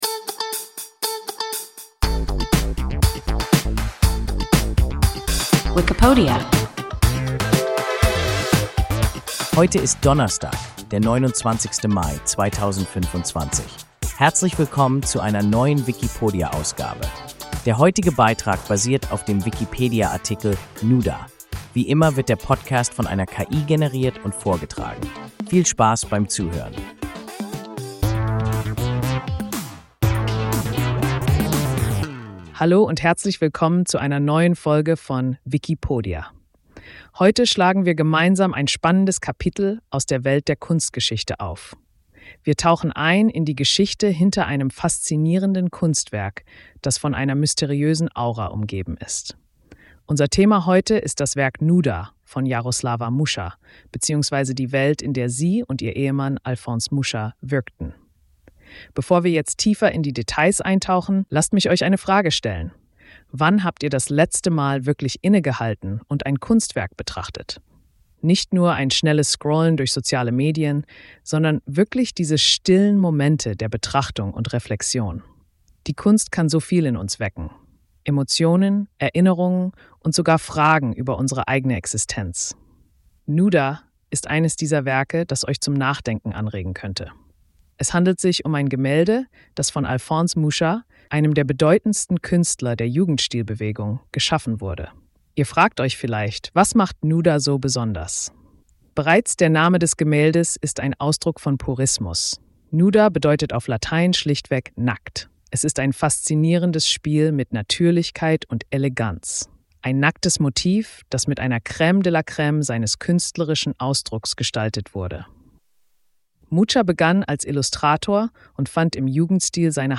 Nuda – WIKIPODIA – ein KI Podcast